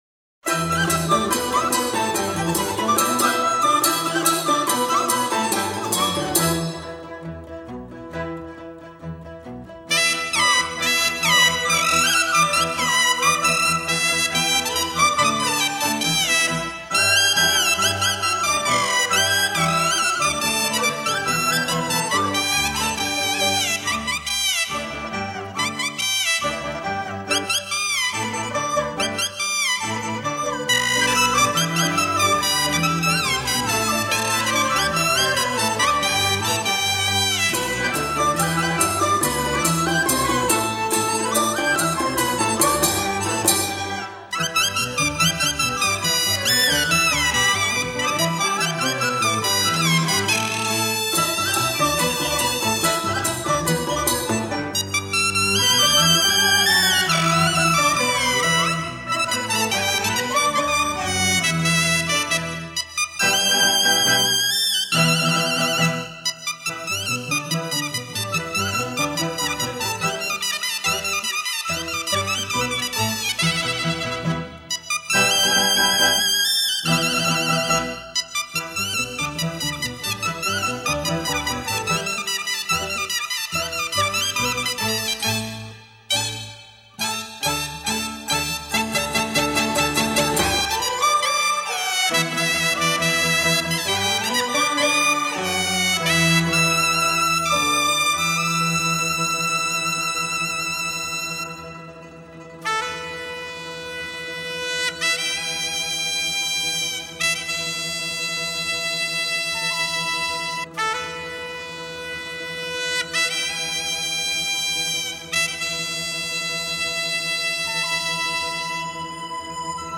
以东北民歌音调为基础
乐曲轻快而又诙谐。